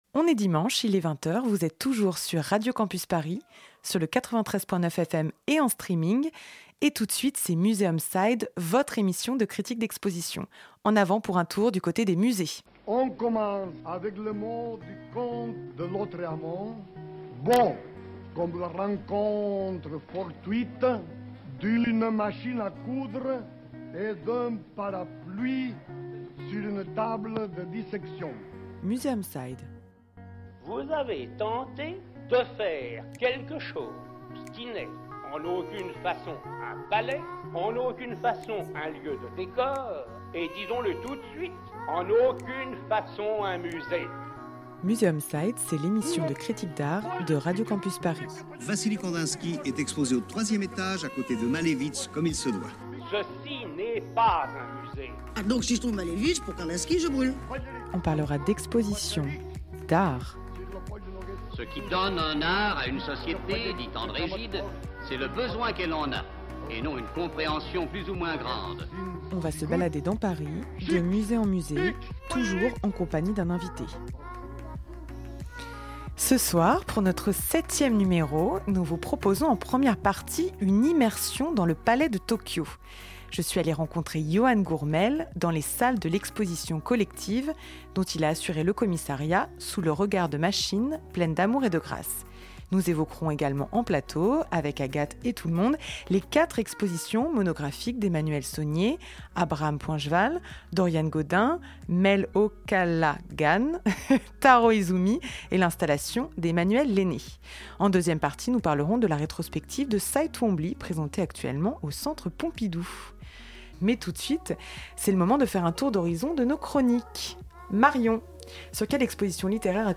Le principe chaque mois : deux expositions à l'affiche dans de grands musées parisiens sur lesquelles nous débattons avec à chaque fois un invité spécialiste qui nous donne des clés de compréhension.
Nous évoquerons également en plateau les quatre expositions monographiques d'Emmanuel Saulnier, Abraham Poincheval, Taro Izumi, Mel O'Callaghan et l'installation d'Emmanuelle Lainé.